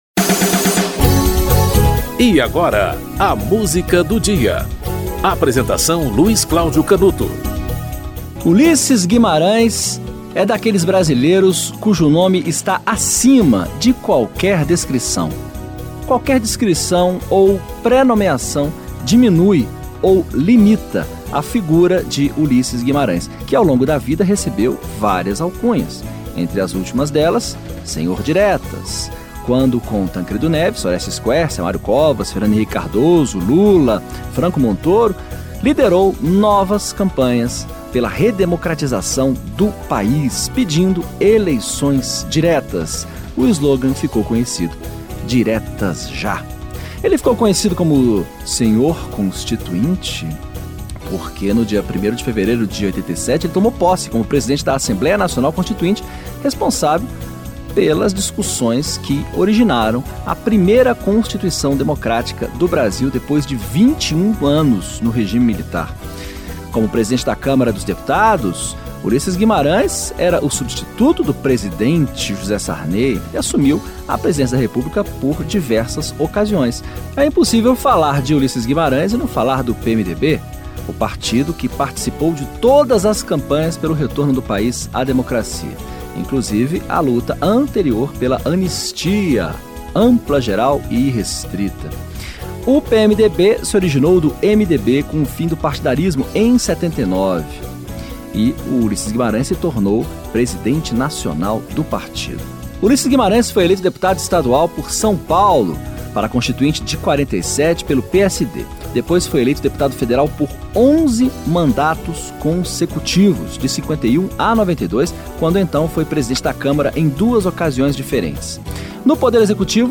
Bote Fé no Velhinho (jingle da candidatura Ulysses na campanha presidencial de 1989)
O programa apresenta, diariamente, uma música para "ilustrar" um fato histórico ou curioso que ocorreu naquele dia ao longo da História.